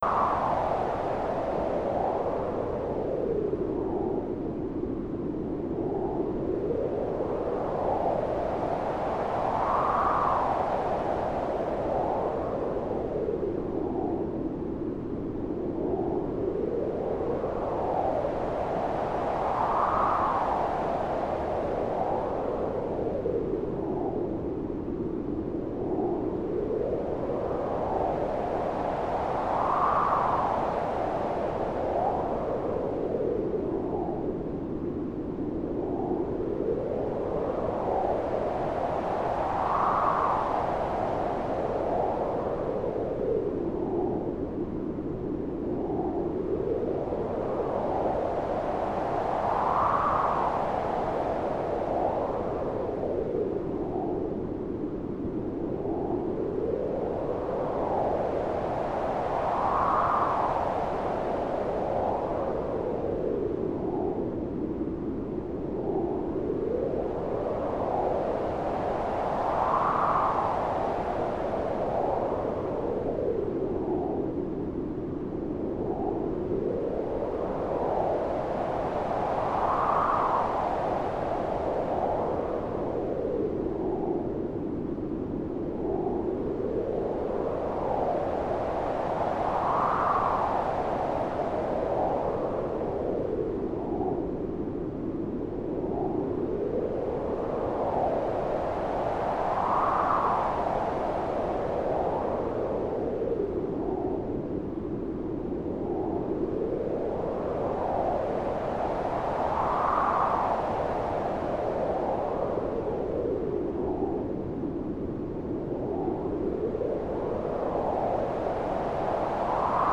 Wind Sound in Market
FastWind.wav